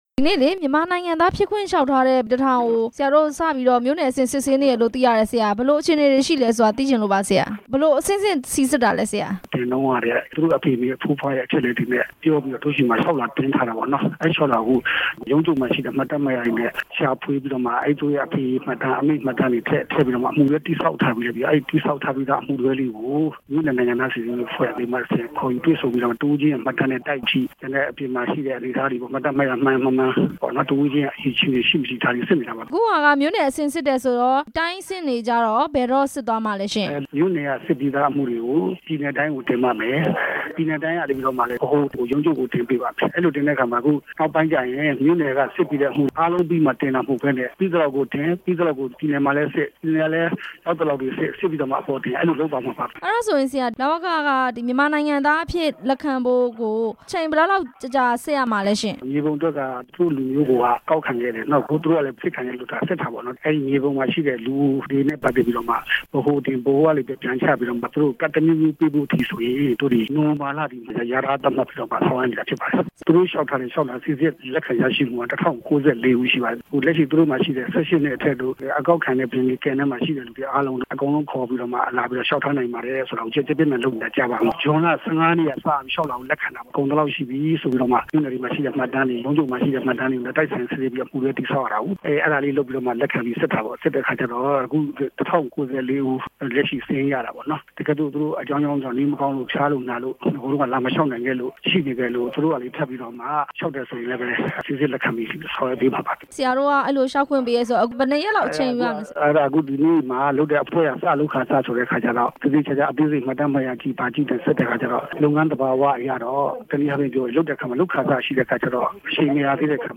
လ.ဝ.က ညွှန်ကြားရေးမှူး ဦးခင်စိုးနဲ့ မေးမြန်းချက်